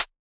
RDM_TapeB_SY1-ClHat.wav